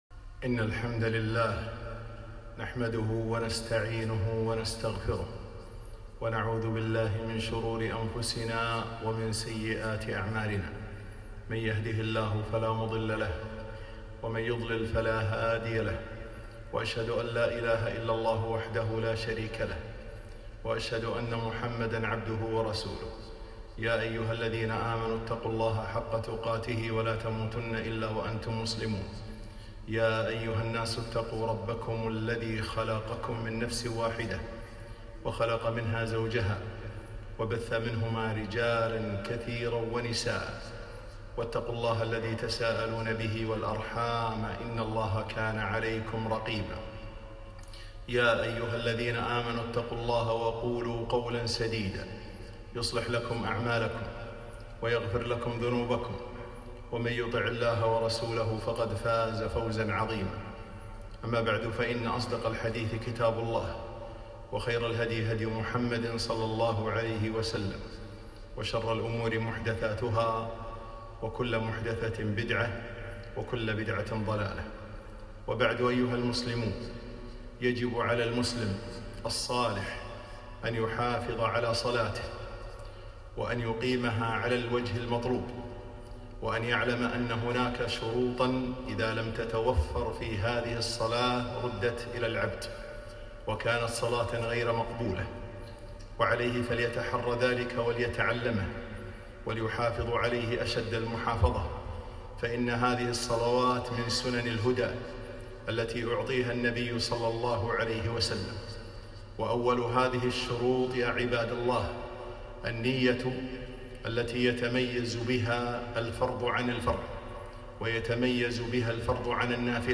خطبة - خطبة من احكام الصلاة 7-4-1443